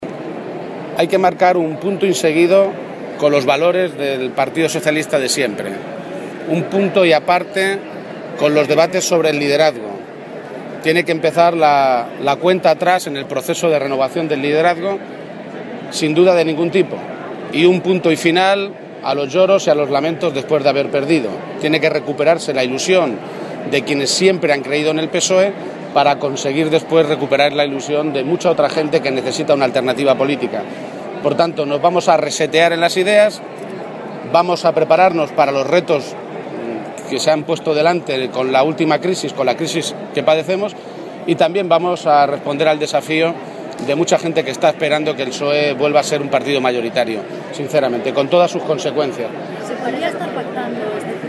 Emiliano García-Page ante los distintos medios de comunicación